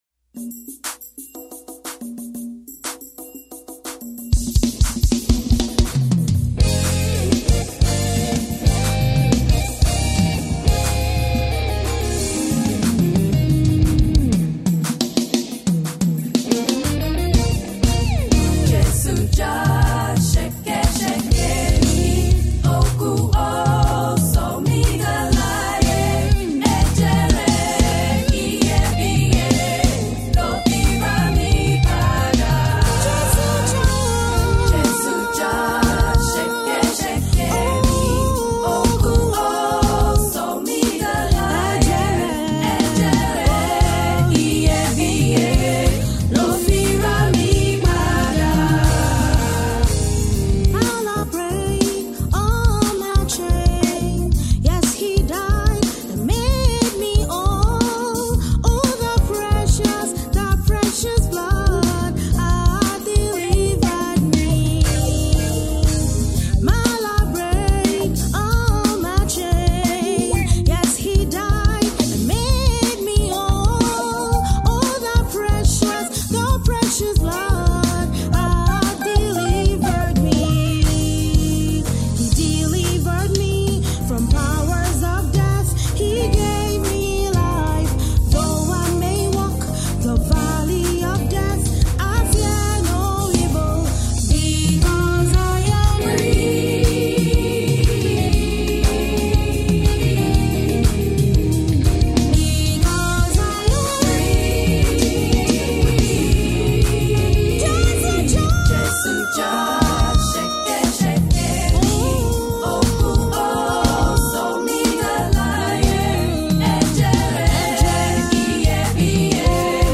inspirational Single
Gospel music